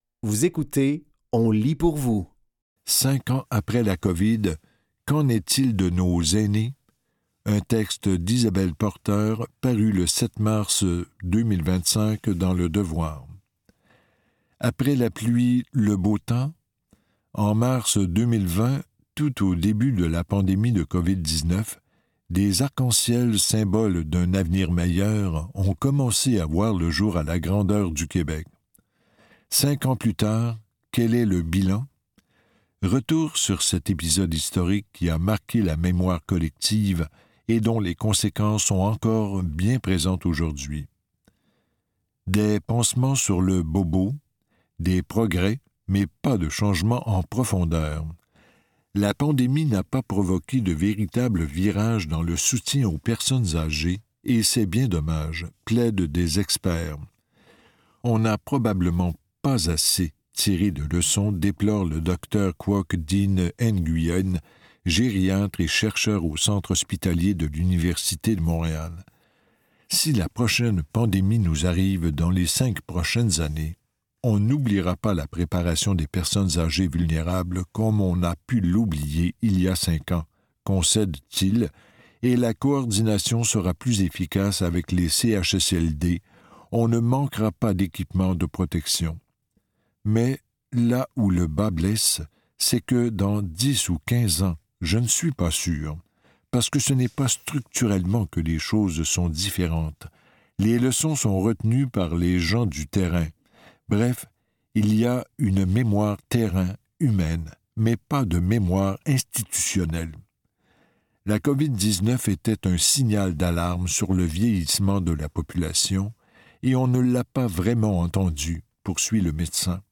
Dans cet épisode de On lit pour vous, nous vous offrons une sélection de textes tirés du média suivant : Le Devoir, La Presse et Fugues.